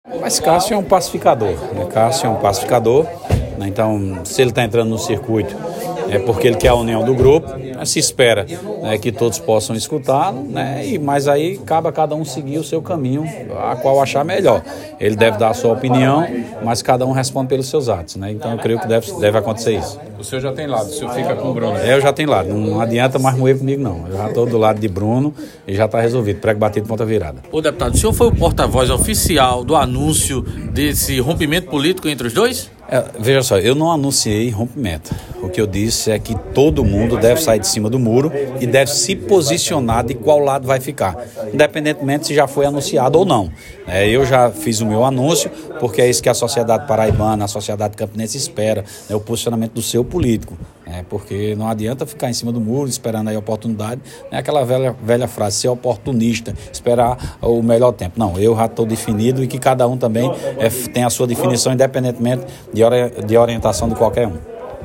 Abaixo a fala do deputado Sargento Neto.